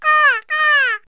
bird5.mp3